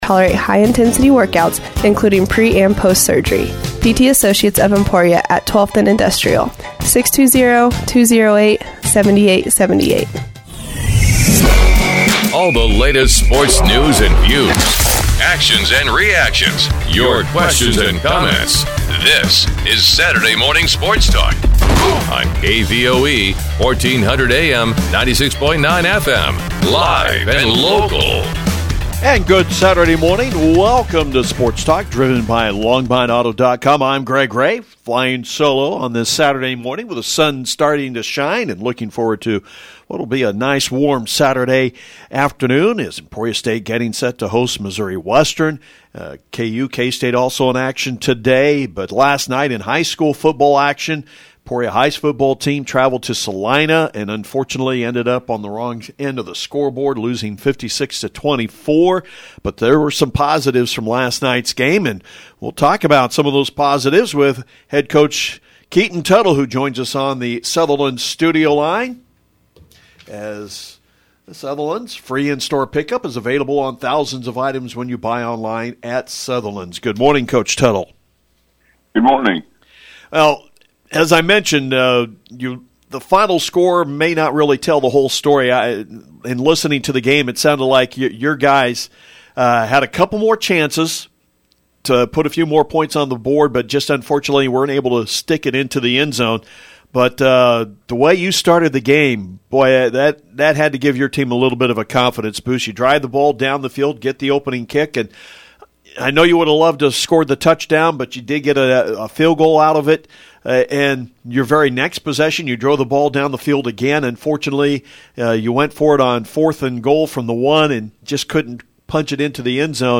sports-talk-9-17.mp3